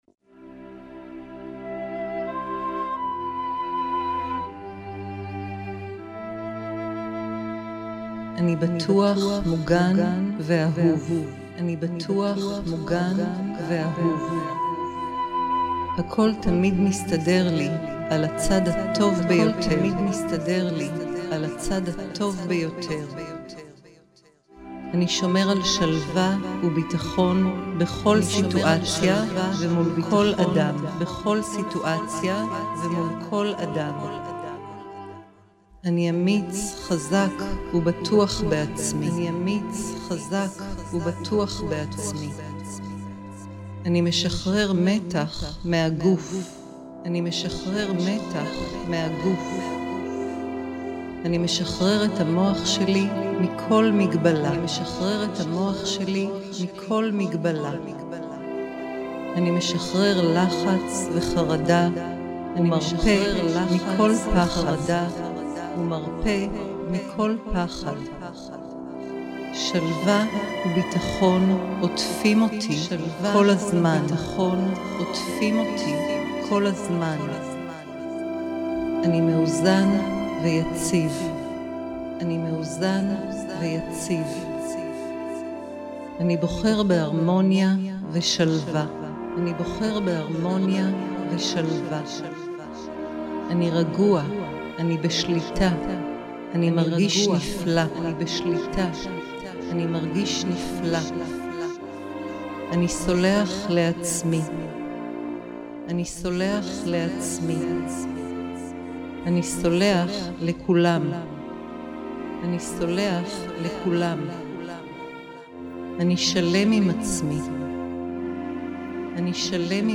מסרים גלויים לשחרור פחד וחרדה לגברים